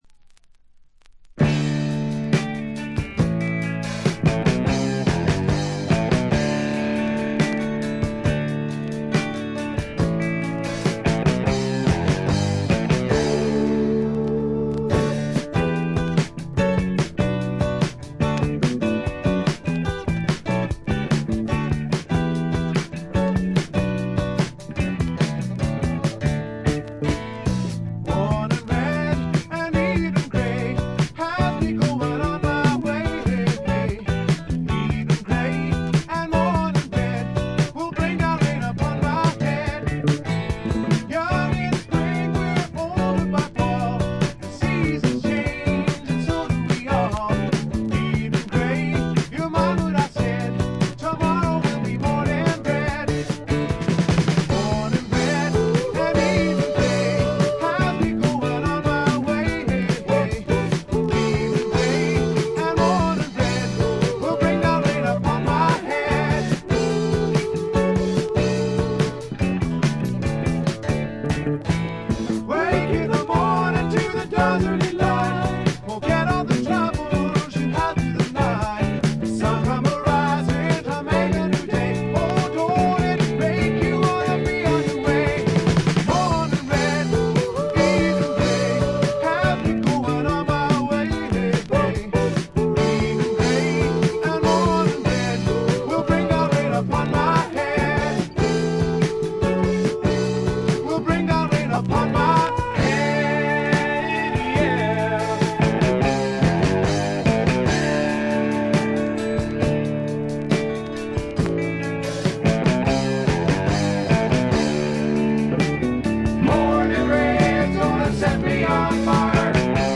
チリプチ多め、散発的なプツ音も少々出ますが、普通に鑑賞できるものと思います。
知名度はいまいちながら実力派のいぶし銀のカントリーロックを聴かせます。
試聴曲は現品からの取り込み音源です。